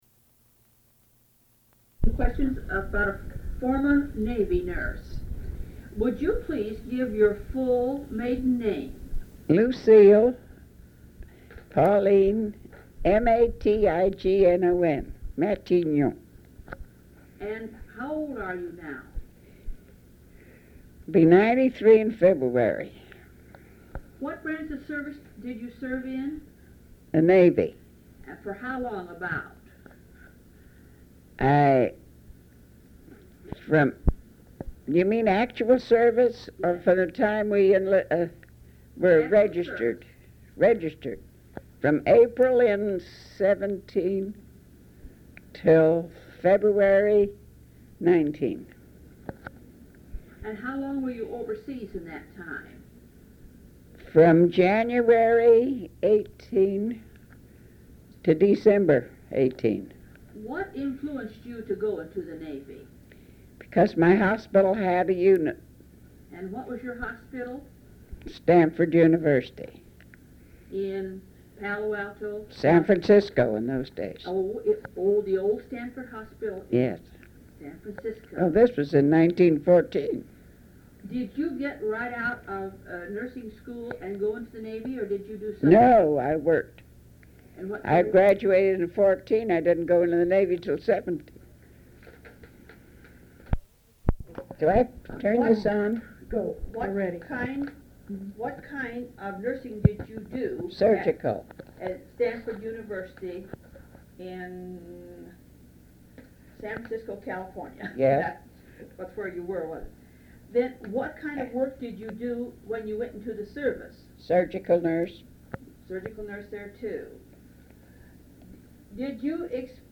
The interviewer is unidentified.
Interviews